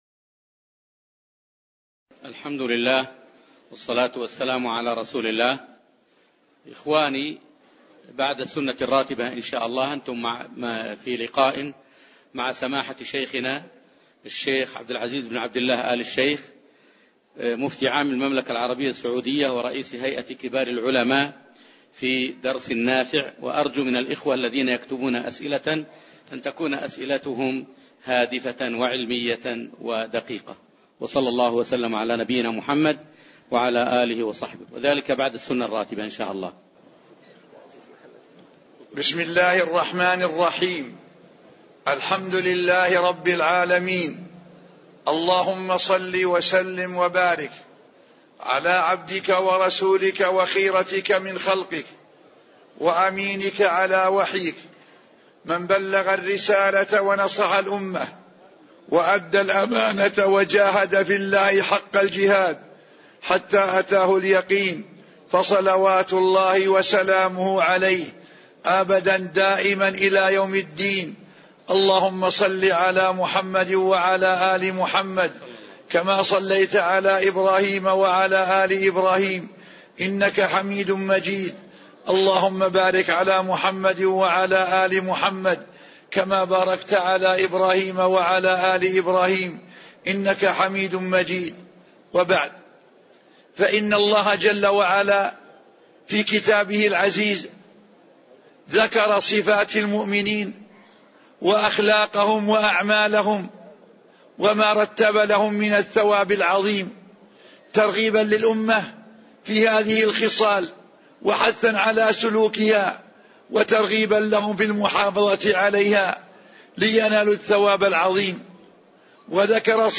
محاضرة صفات المؤمنين وفيها: اشتمال آيات سورة المؤمنون على صفات المؤمنين، وحقيقة الإيمان، وأهمية صلاح القلب، ما ناله المؤمنون من جزاء
تاريخ النشر ١٢ ربيع الثاني ١٤٣١ المكان: المسجد النبوي الشيخ: سماحة المفتي الشيخ عبدالعزيز بن عبدالله آل الشيخ سماحة المفتي الشيخ عبدالعزيز بن عبدالله آل الشيخ صفات المؤمنين